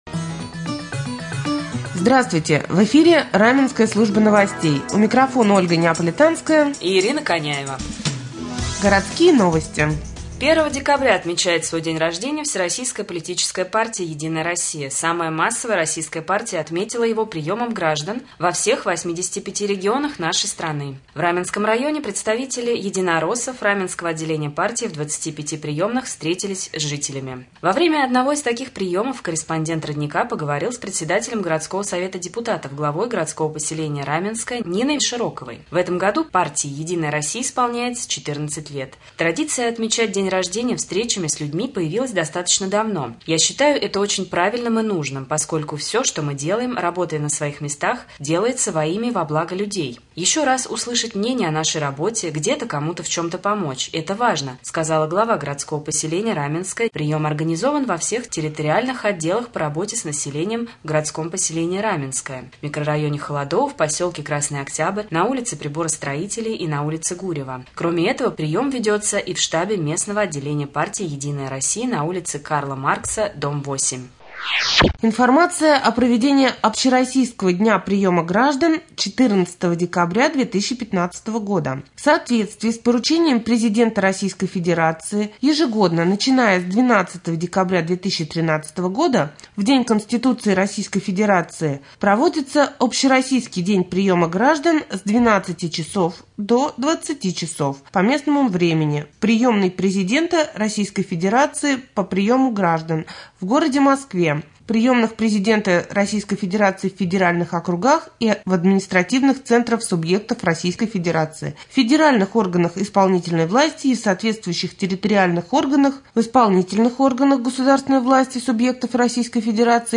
1. О работе депутатского корпуса рассказал Владимир Кирьяков
2. Новости
3.Рубрика «Специальный репортаж». 1 декабря в Раменском парке прошла праздничная акция «Зимний парк».